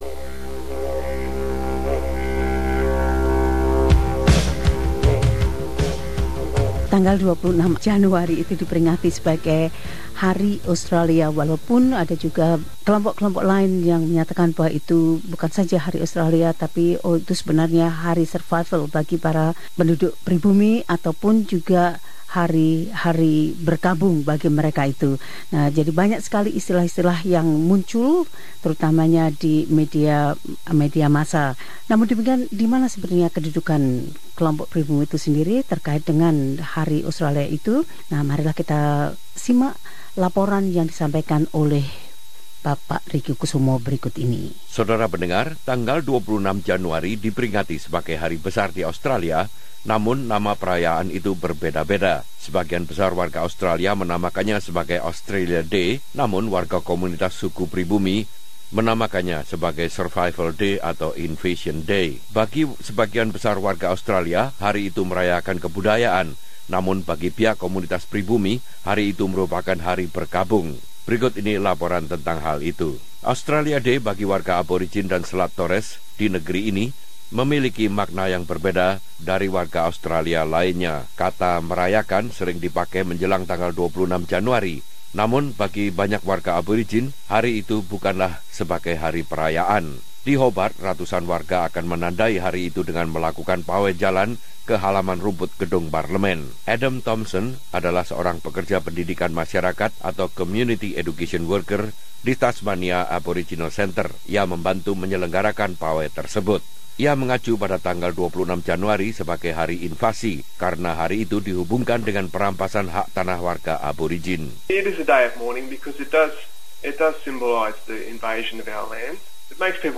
Laporan ini menjelaskan.